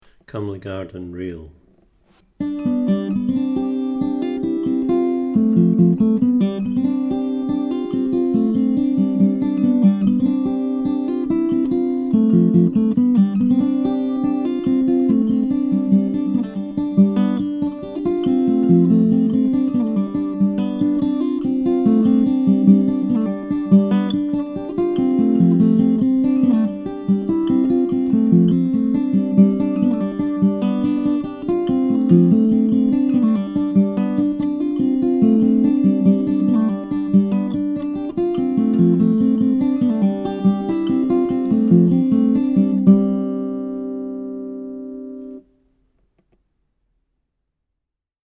DIGITAL SHEET MUSIC - FINGERPICKING GUITAR SOLO